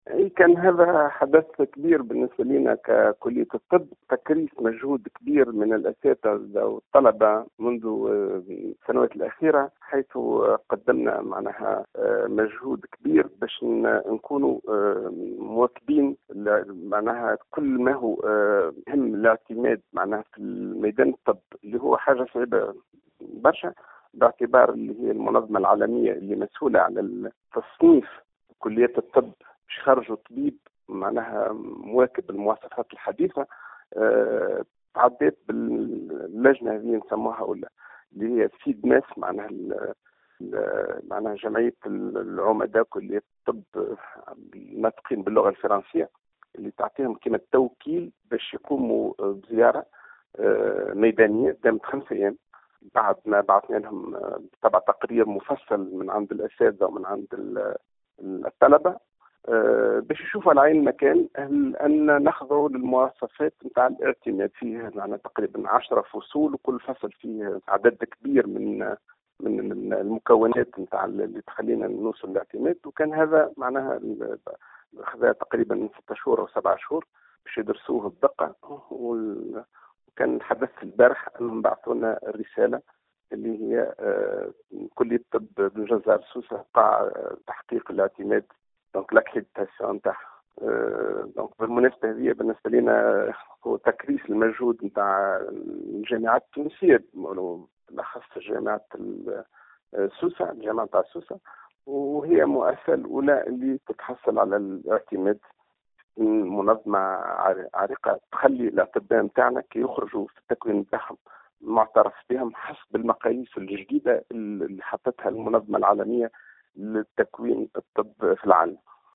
وفي تصريح للجوهرة أف أم